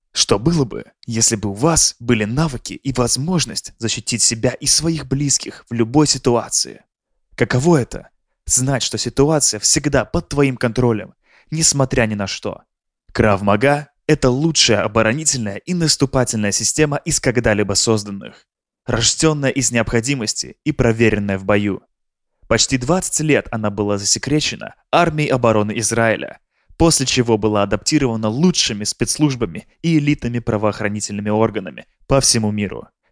特点：大气浑厚 稳重磁性 激情力度 成熟厚重
风格:浑厚配音